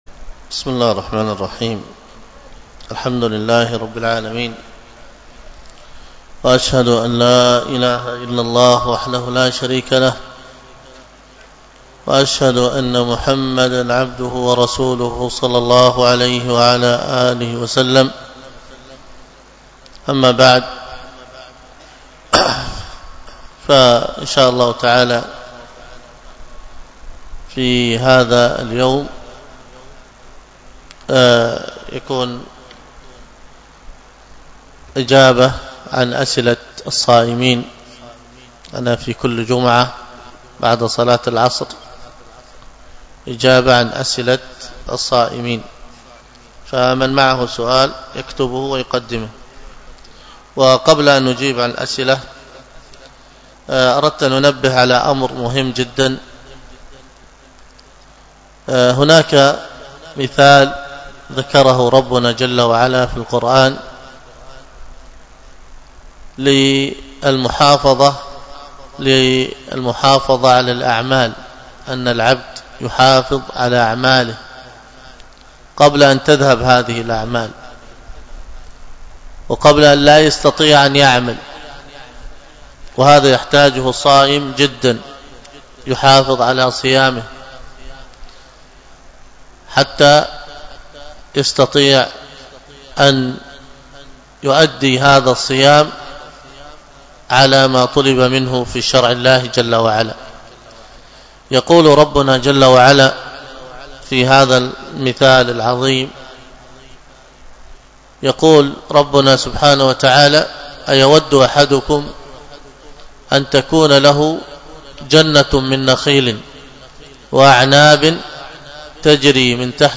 الدرس في شرح فتح الوهاب 1 4، الدرس الرابع ( إذا عرفت ذلك فاعلم أن رفع إلي رسالة لرجل فارسي ...